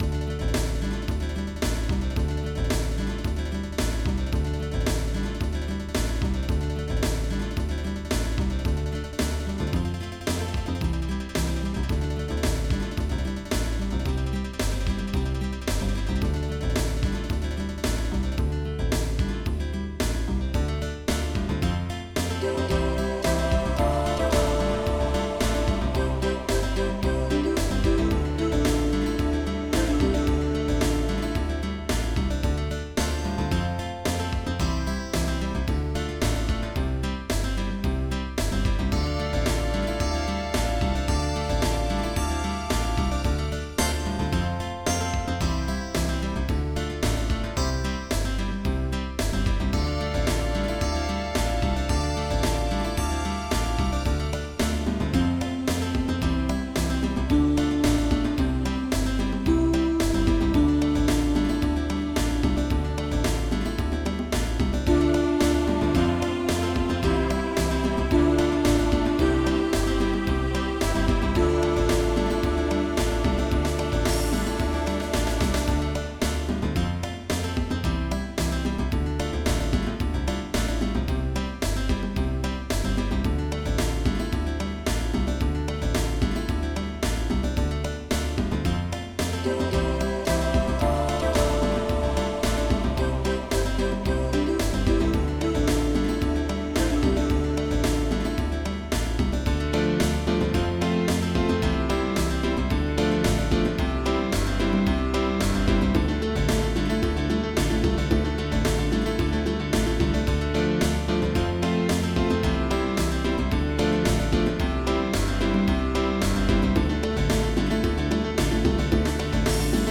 Oldies
MIDI Music File
General MIDI